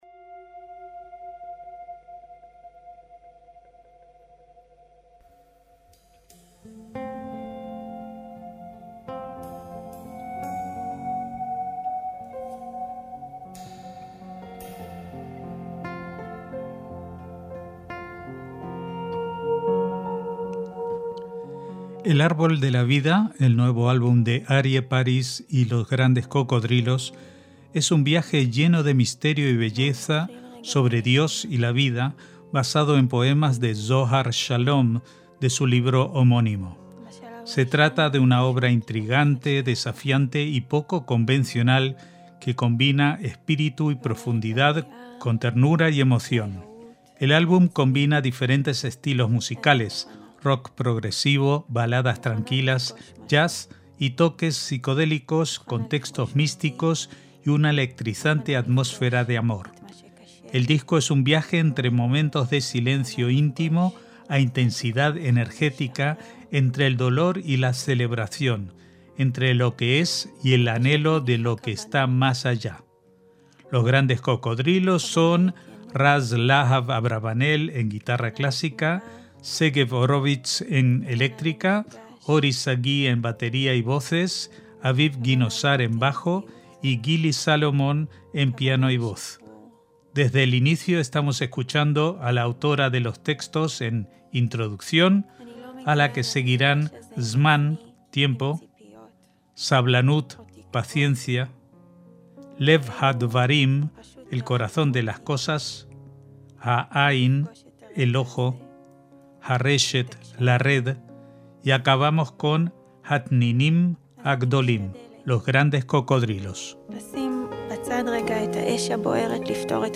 El disco es un viaje entre momentos de silencio íntimo a intensidad energética, entre el dolor y la celebración, entre lo que es y el anhelo de lo que está más allá.
El álbum combina diferentes estilos musicales: rock progresivo, baladas tranquilas, jazz y toques psicodé